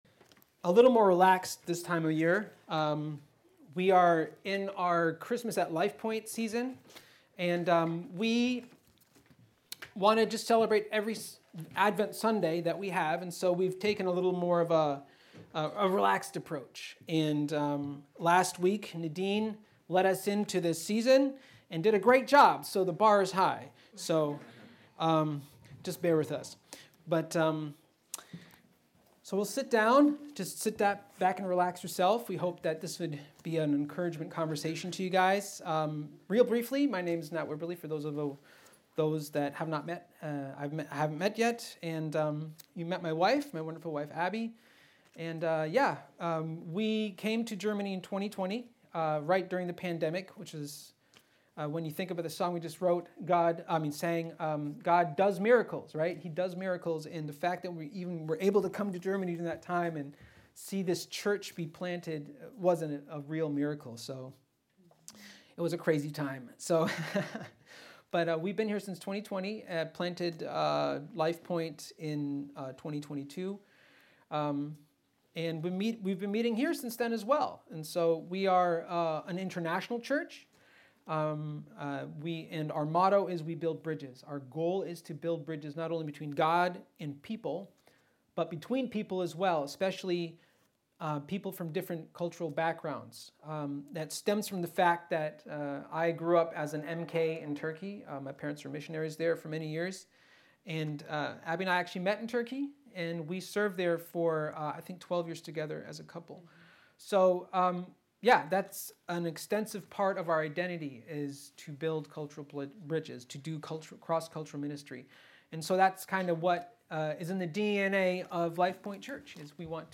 At the heart of this sermon is the assurance that God goes with us.